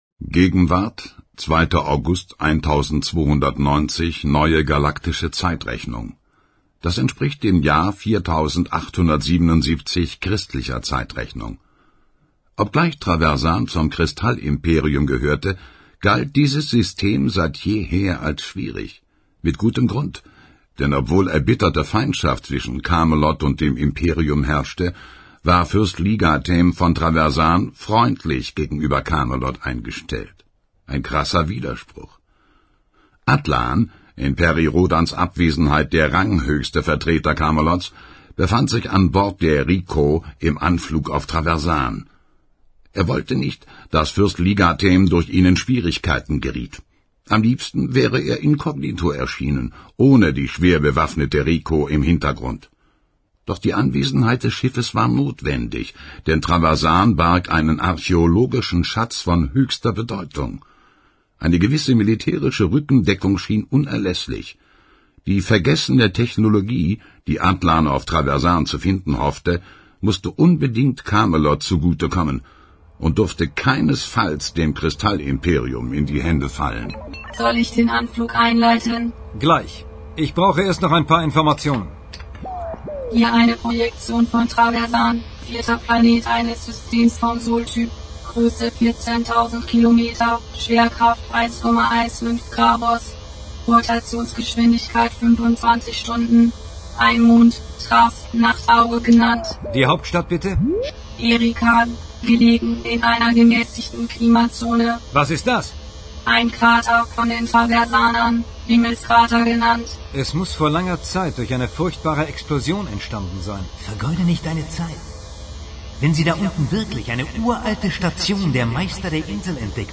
Perry Rhodan Hoerbuch 10+11